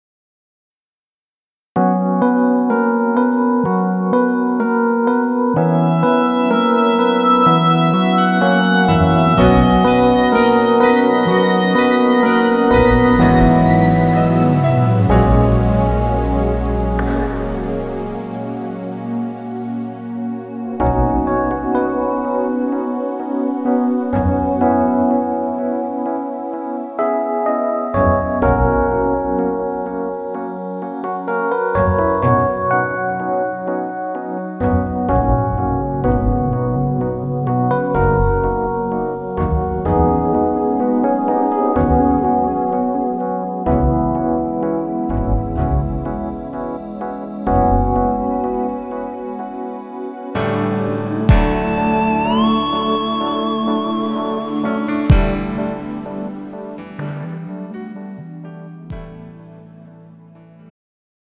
음정 -1키
장르 축가 구분 Pro MR
가사   (1절 앞소절 -중간삭제- 2절 후렴연결 편집)